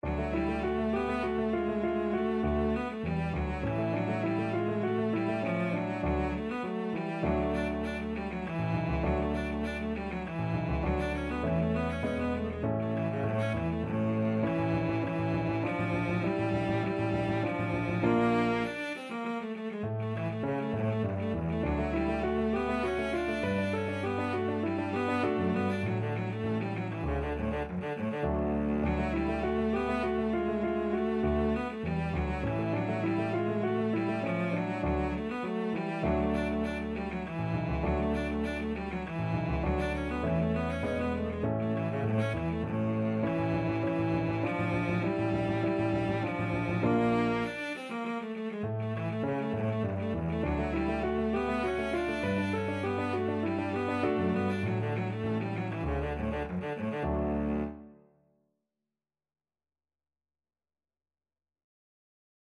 3/4 (View more 3/4 Music)
D3-D5
Classical (View more Classical Cello Music)